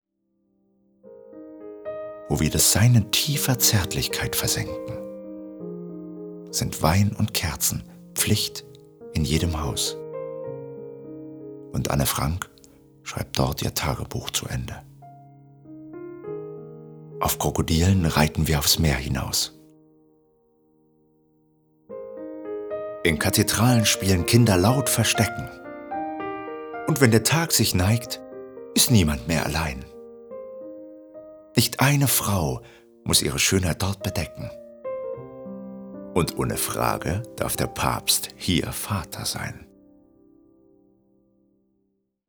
Wunderland Buch inkl. Hörbuch